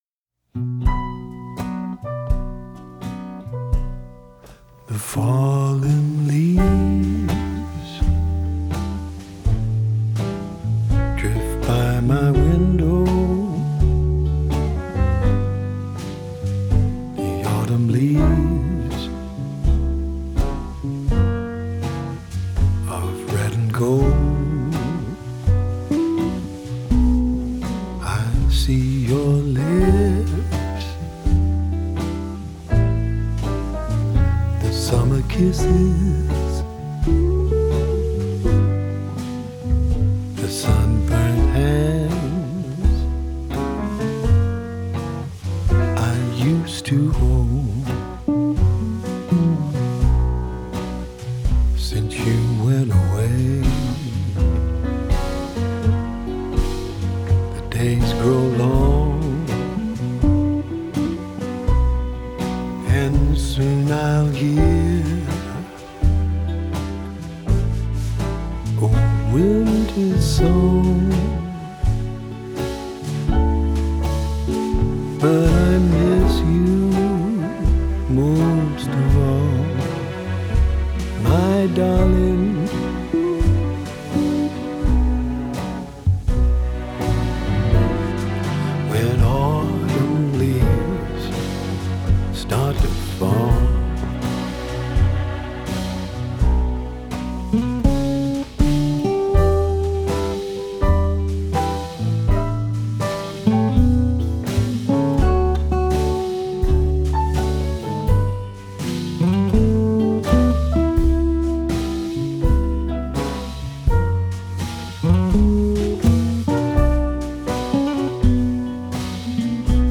блюз
рок-музыка